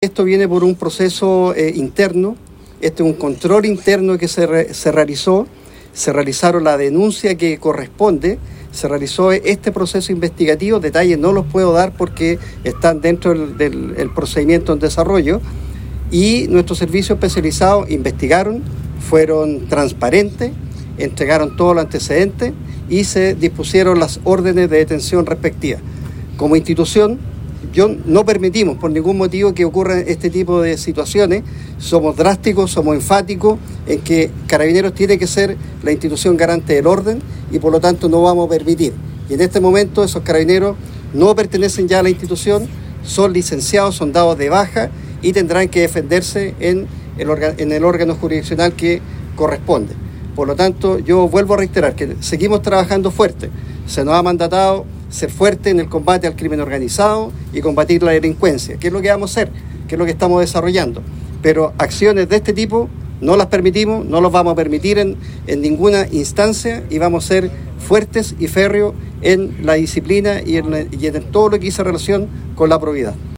El General Director de Carabineros, Marcelo Araya, informó que cursó la baja a los seis funcionarios del OS-7 Aconcagua, detenidos por la investigación que desarrolla la Unidad de Análisis Criminal y Focos Investigativos de la Fiscalía Regional de Valparaíso.